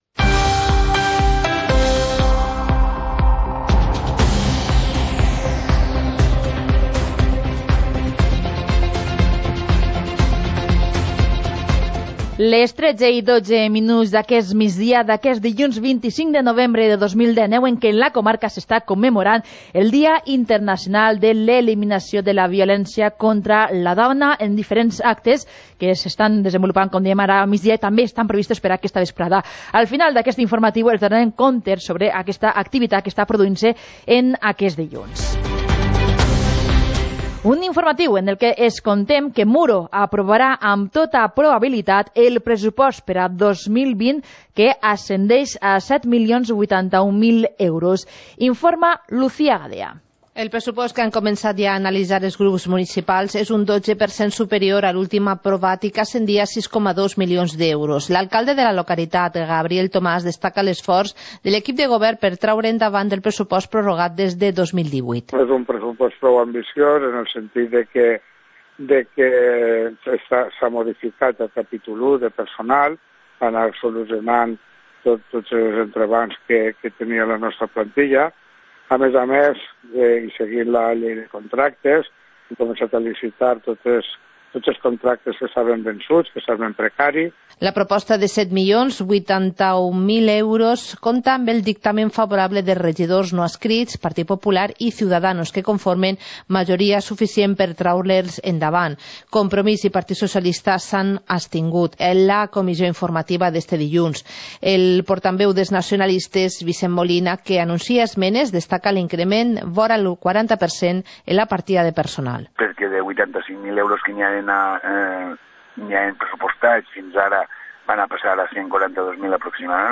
Informativo comarcal - lunes, 25 de noviembre de 2019